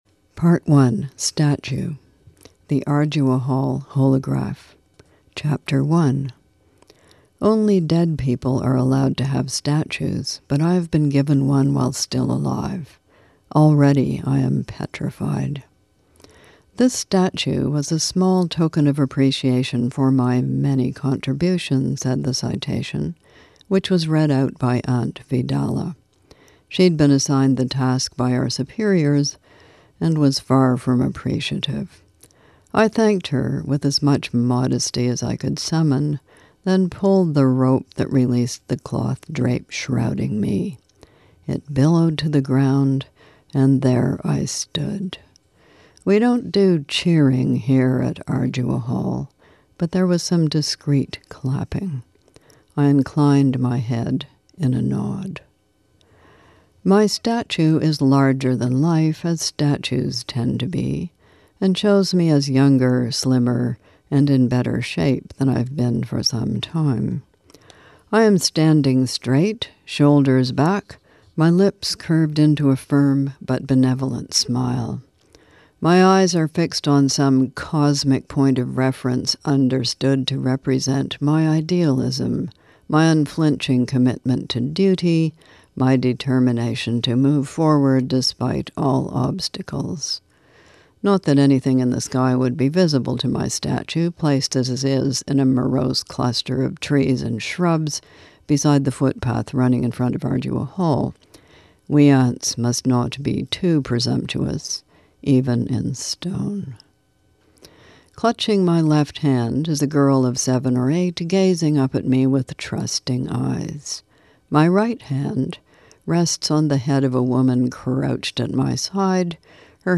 Hear Margaret Atwood Read From 'The Testaments,' Her Sequel To 'The Handmaid's Tale'